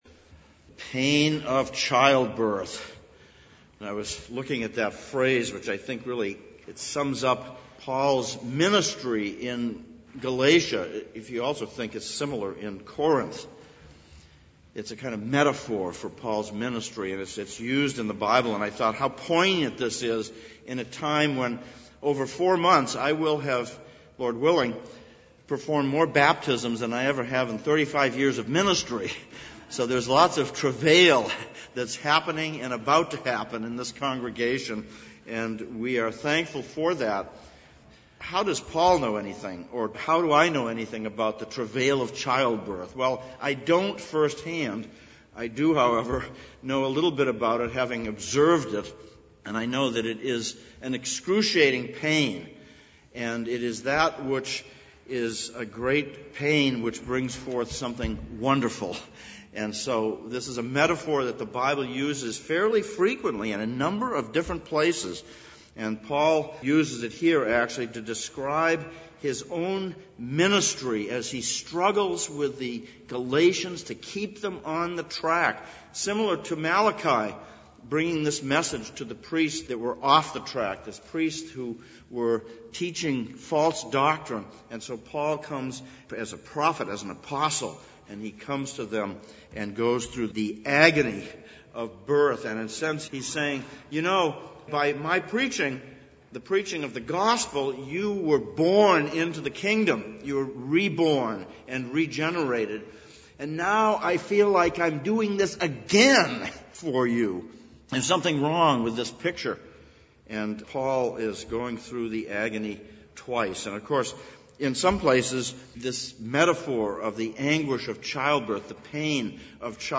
Passage: Galatians 4:1-20, Malachi 2:1-9 Service Type: Sunday Morning Sermon